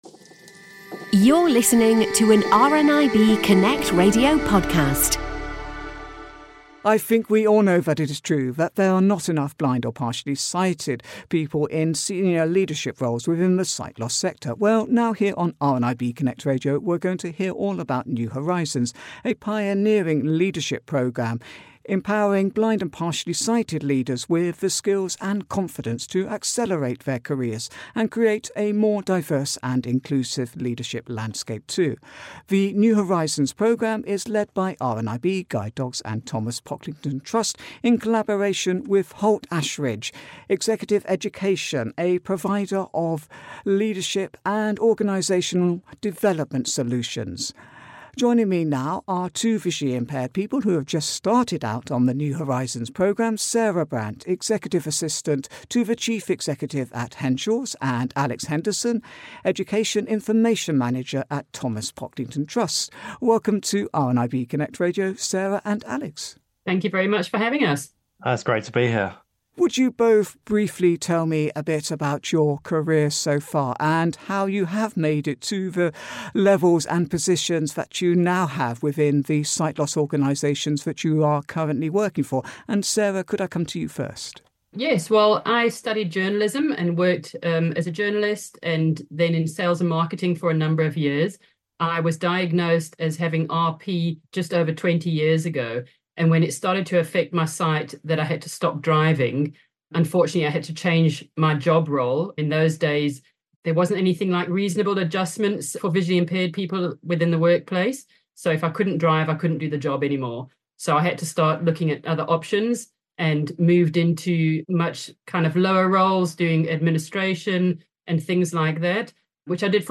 caught up with two visually impaired leaders working in the sight loss sector